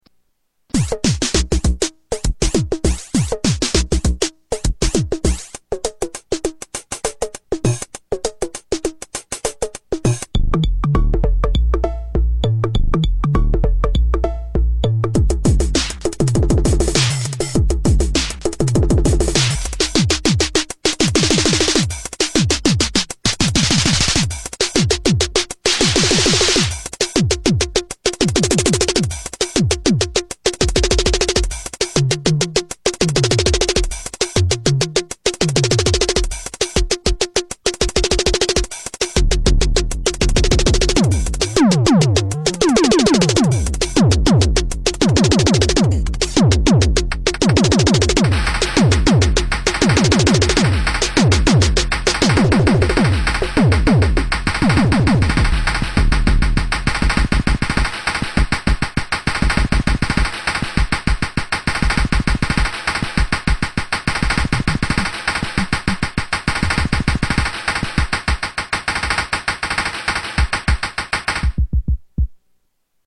JOMOX XBase09SE Demo
Tags: Sound Effects JoMoX Sounds JoMoX XBase AirBase